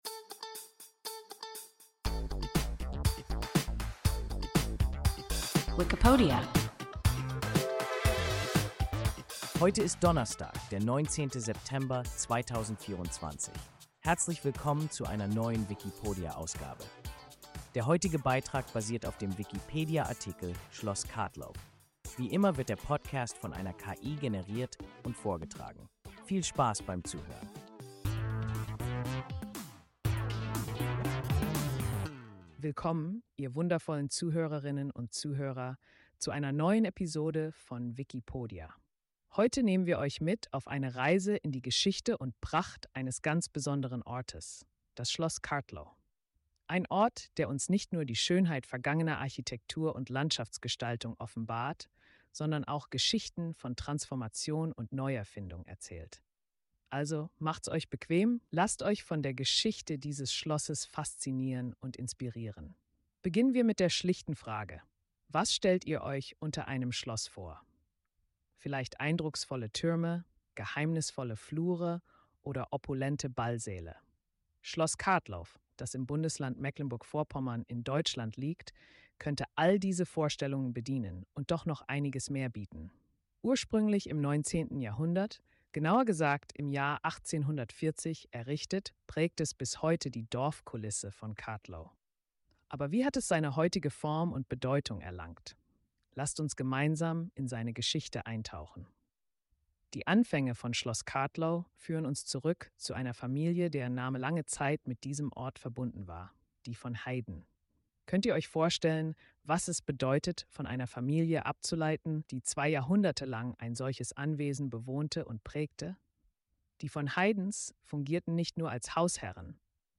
Schloss Kartlow – WIKIPODIA – ein KI Podcast